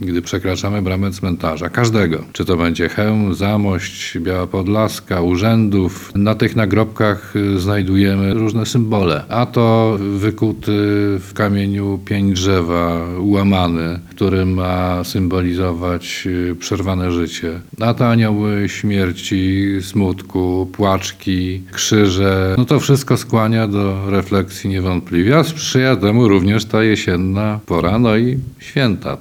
– Te nagrobki, które widzimy, często zachęcają nas do różnych refleksji – mówi Lubelski Wojewódzki Konserwator Zabytków Dariusz Kopciowski.